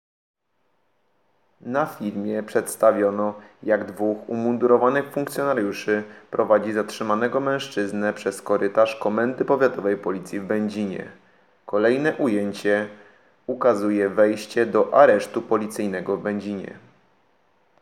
Nagranie audio audiodeskrypcja_acc.m4a